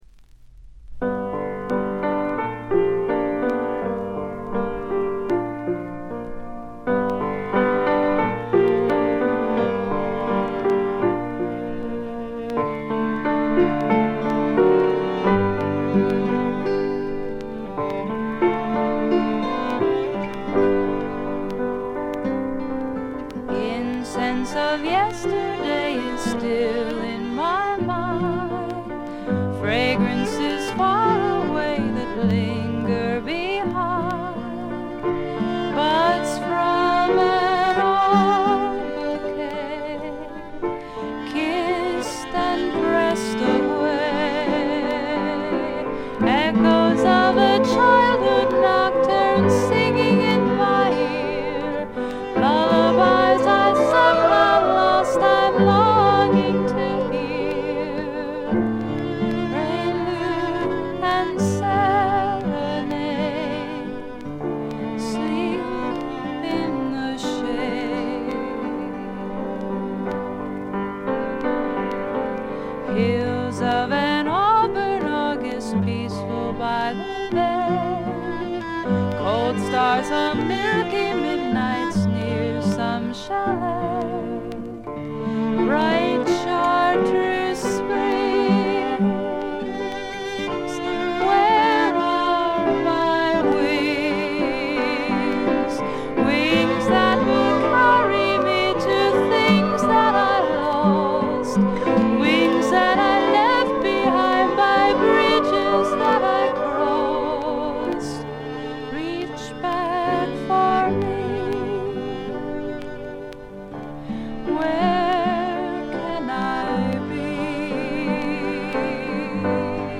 ほぼ全曲自作もしくは共作で、ギター弾き語りが基本の極めてシンプルな作りです。
試聴曲は現品からの取り込み音源です。
※B4-B5連続です。曲間ノイズ。